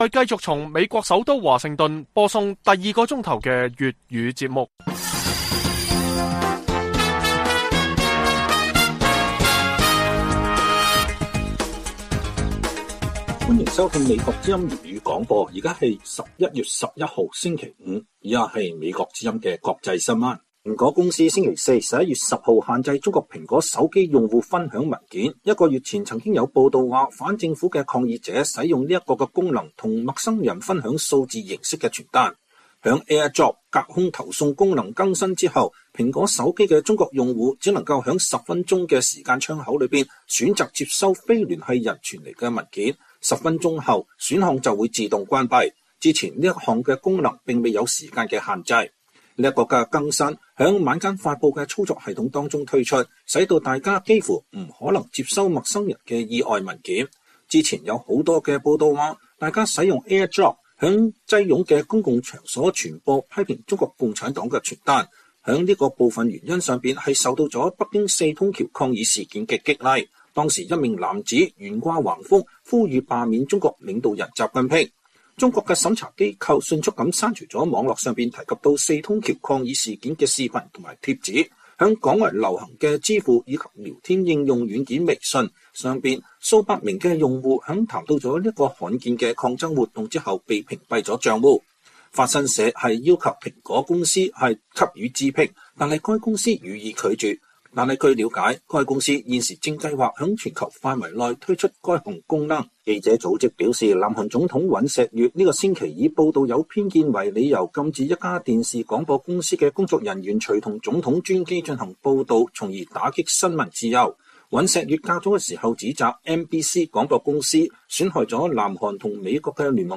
粵語新聞 晚上10-11點: 美中期選舉或將令拜習會平添新的挑戰